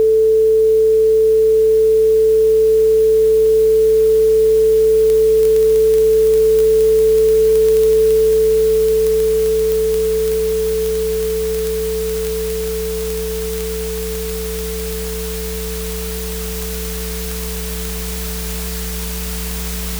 This is the sound of the binder letting go.
0:05: The stickiness begins. The flutter isn’t in the recording; it’s the tape physically jerking across the head.
0:08: The oxide sheds. The high frequencies vanish as the gap clogs with the debris of the past.
0:15: Total failure. The machine hum becomes the only surviving frequency.